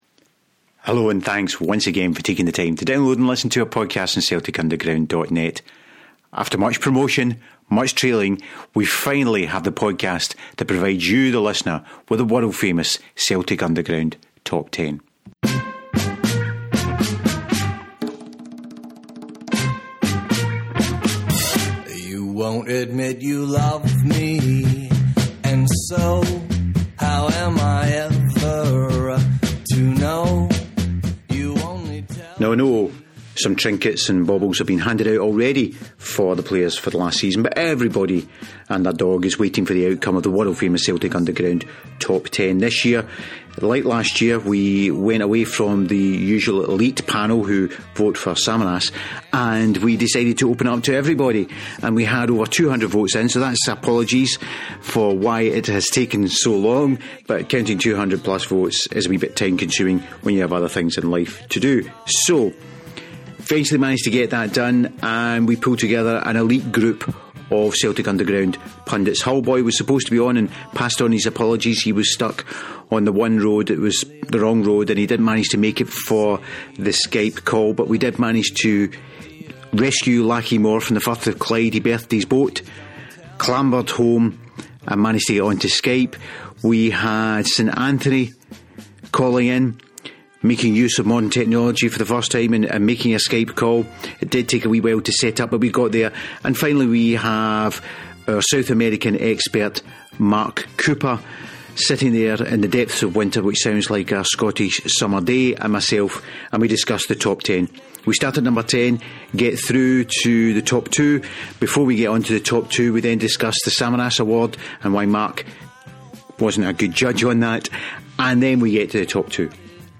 We pulled together some of the team and we all sat around a Skype mic and ran through that ten.